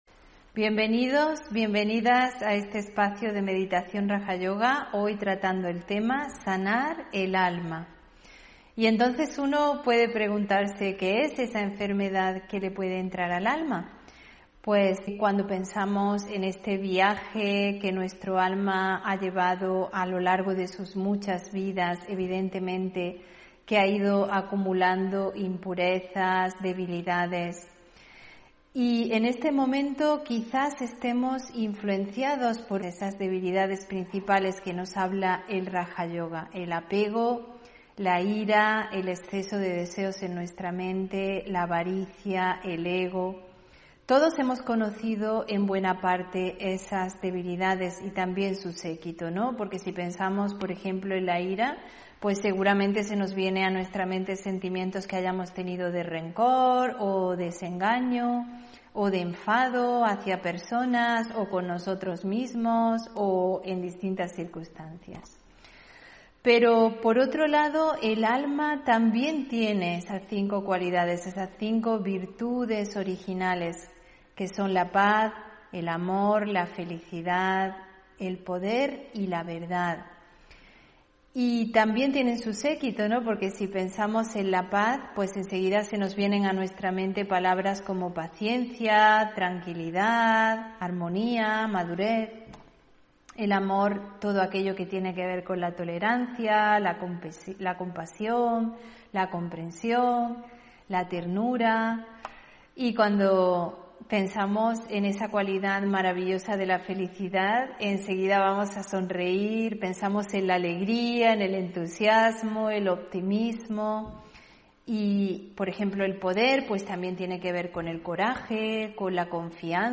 Meditación y conferencia: Sanar el alma (20 Diciembre 2021)